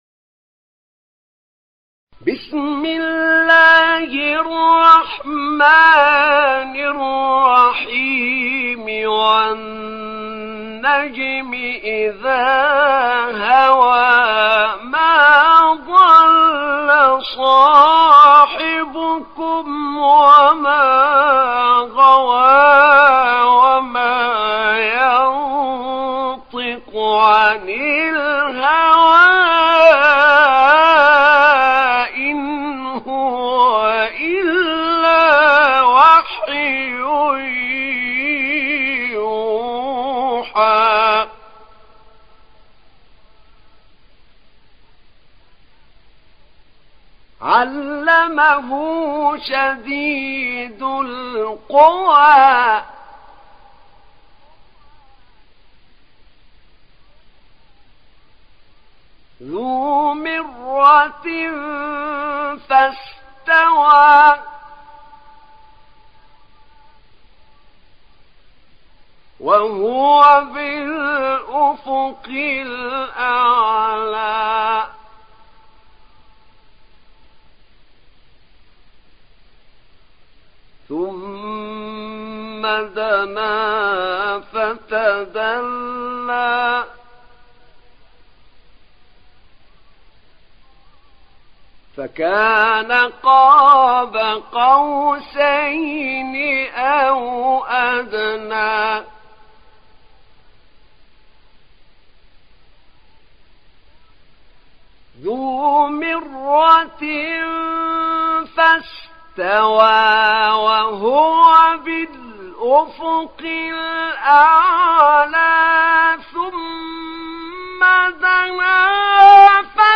تحميل سورة النجم mp3 بصوت أحمد نعينع برواية حفص عن عاصم, تحميل استماع القرآن الكريم على الجوال mp3 كاملا بروابط مباشرة وسريعة